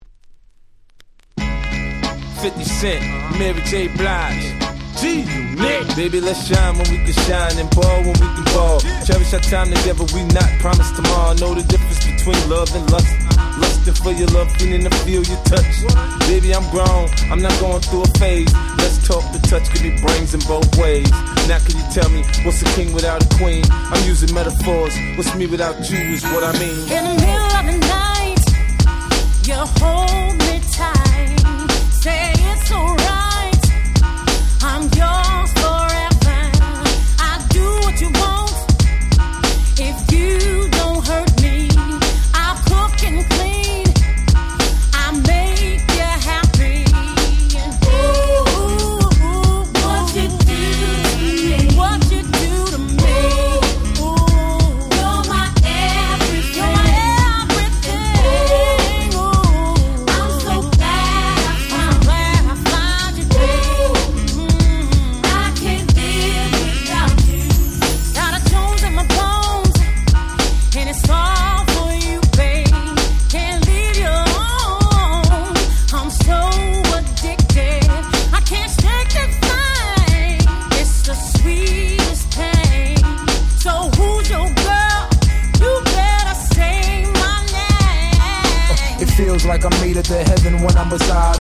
03' Smash Hit R&B !!